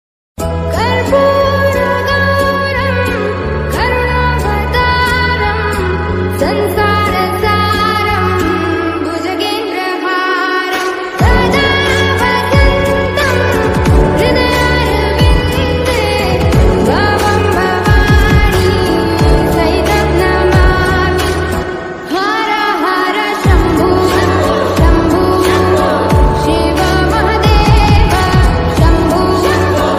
A Mystical Spiritual Song Honoring Lord Shiva